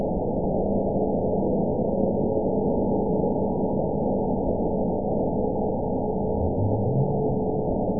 event 921216 date 05/02/24 time 13:55:01 GMT (1 year, 5 months ago) score 9.29 location TSS-AB04 detected by nrw target species NRW annotations +NRW Spectrogram: Frequency (kHz) vs. Time (s) audio not available .wav